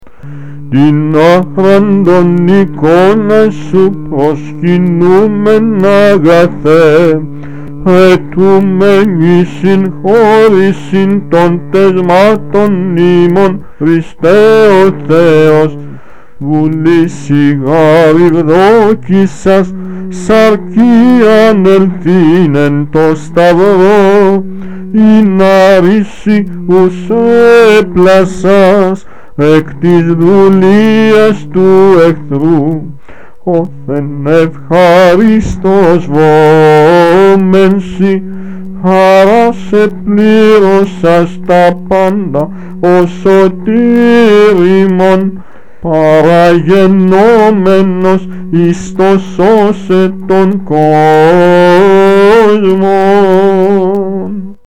Ἦχος β'